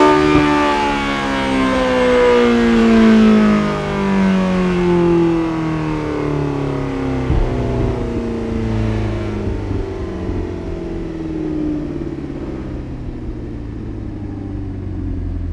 v8_01_decel.wav